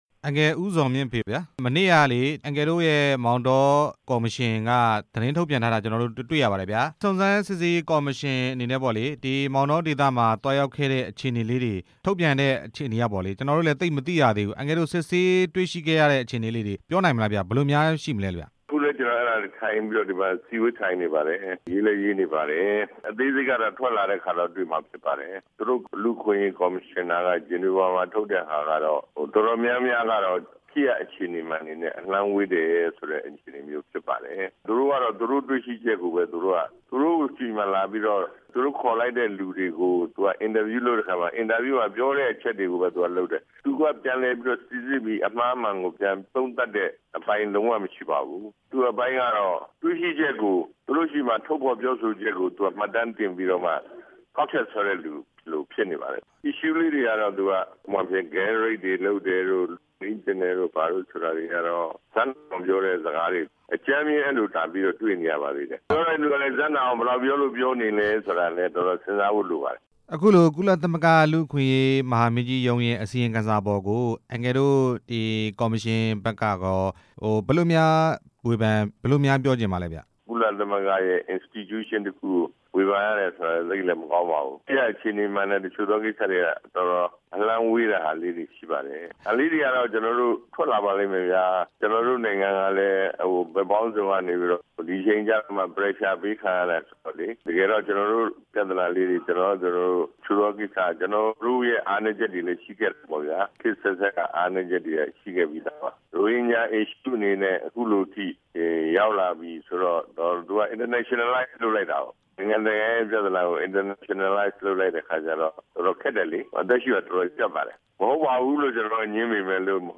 မောင်တောအရေး စုံစမ်းစစ်ဆေးရေးကော်မရှင် ကွင်းဆင်းစစ်ဆေးမှုအပေါ် မေးမြန်းချက်